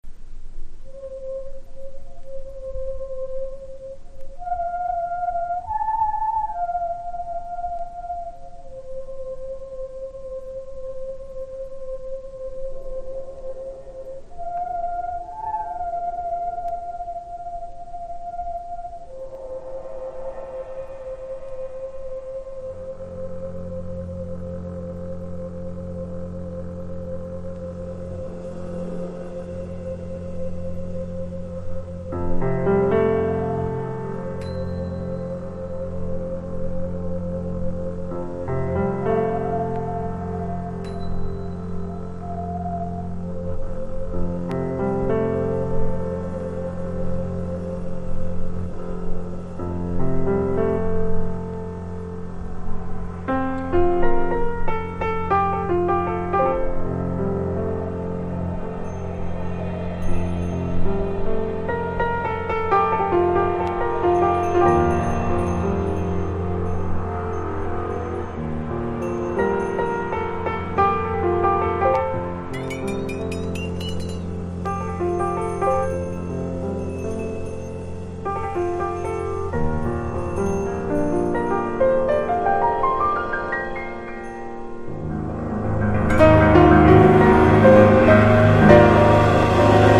日本最大の良質インディペンデント・ジャズ・レーベル
そしてタイトル曲は、土笛の音、鈴の音、弓引きの音などを混ぜ幻想的に仕上げた傑作。